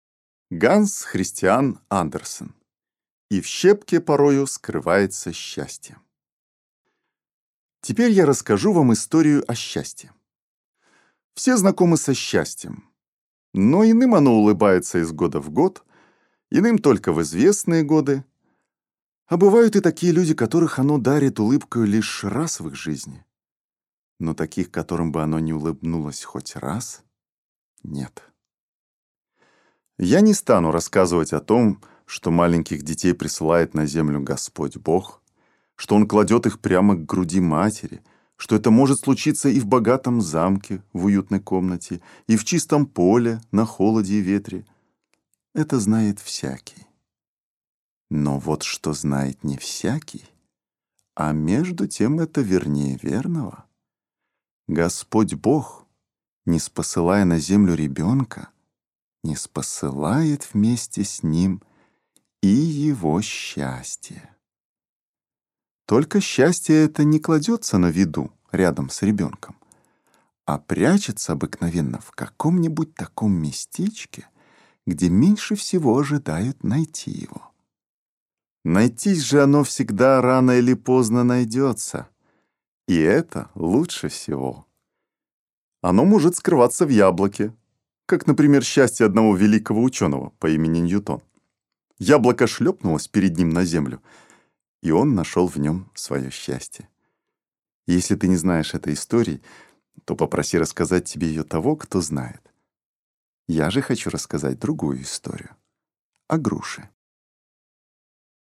Аудиокнига И в щепке порою скрывается счастье!